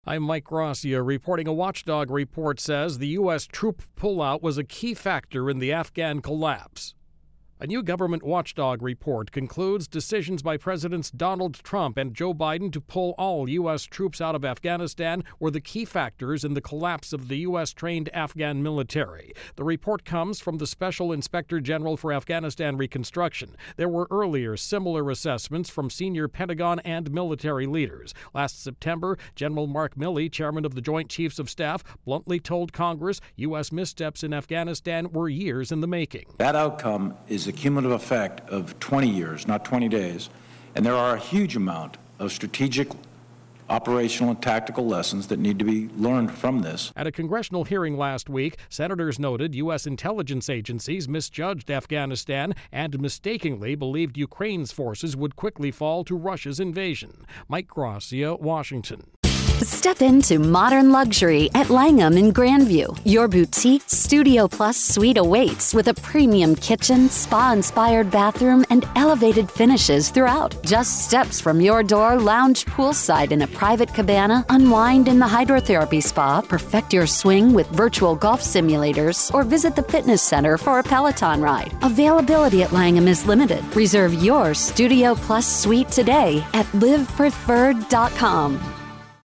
United-States-Afghanistan intro and voicer.